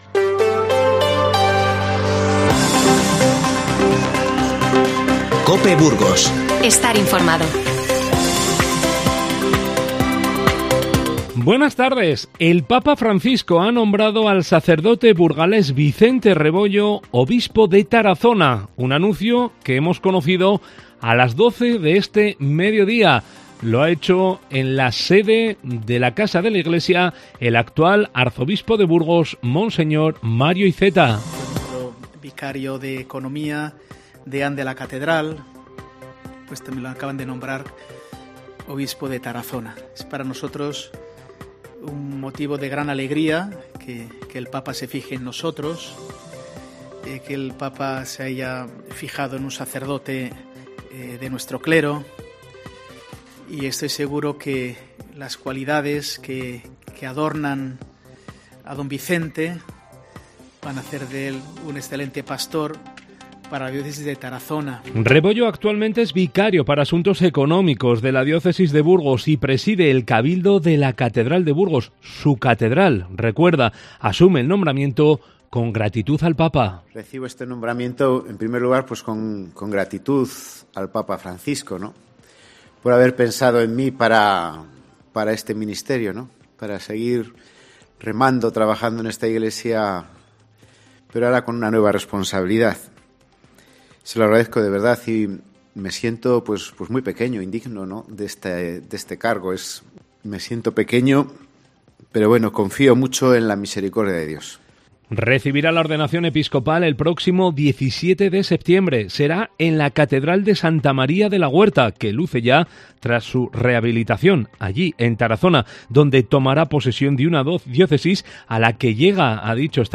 INFORMATIVO Mediodía 28-06-22